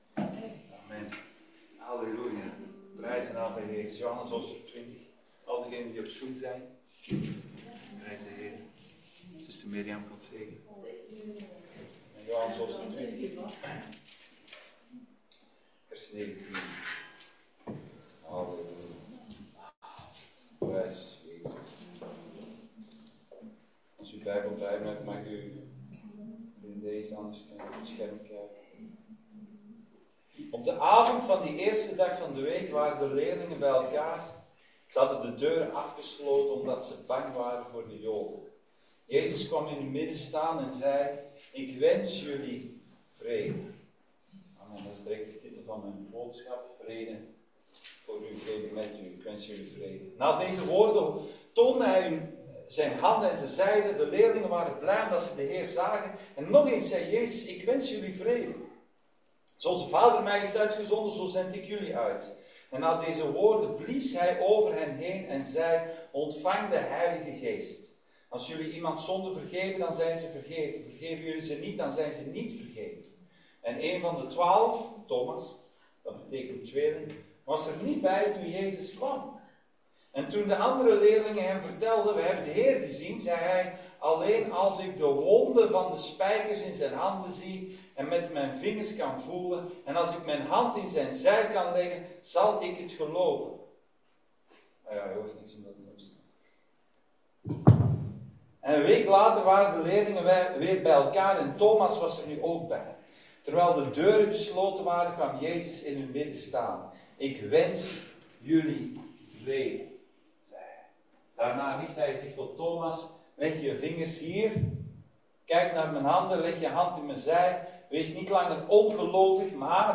Paasdienst – Vrede met U